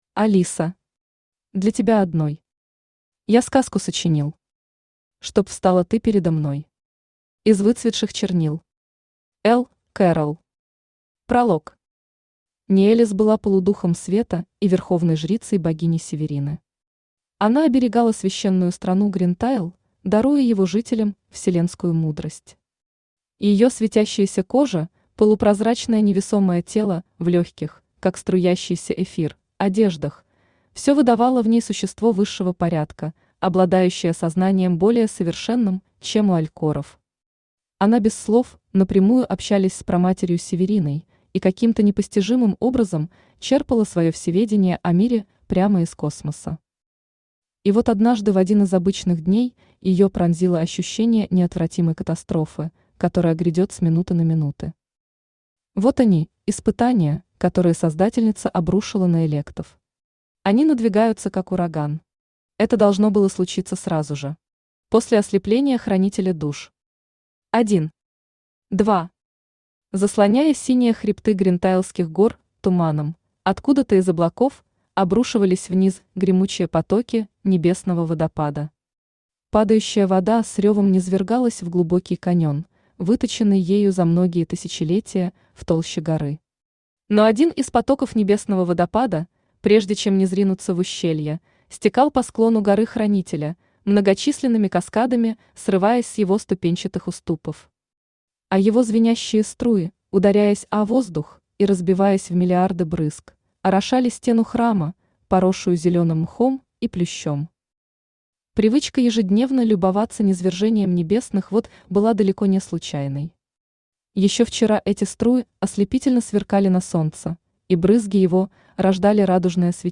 Аудиокнига Когда Хранитель слеп…
Автор Рената Еремеева Читает аудиокнигу Авточтец ЛитРес.